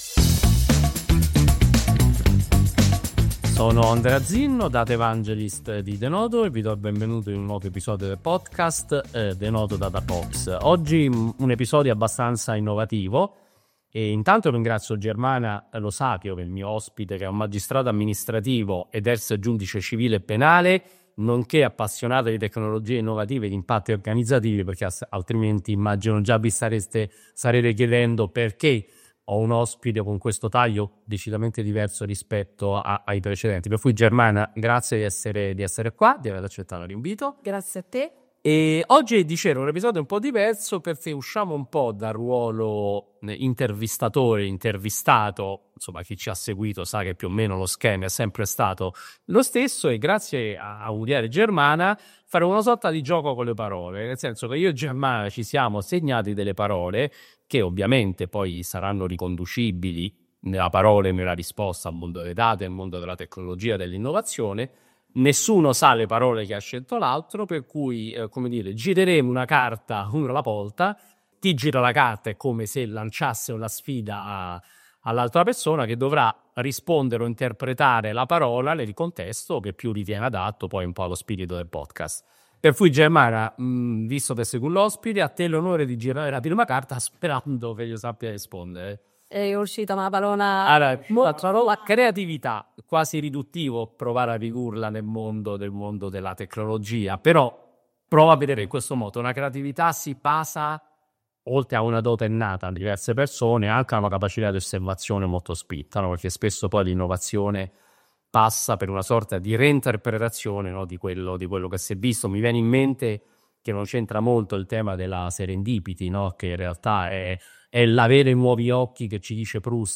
È questo il gioco che abbiamo fatto insieme a Germana Lo Sapio, Magistrato Amministrativo, ex Giudice Civile e Penale e appassionata di tecnologia e impatti organizzativi.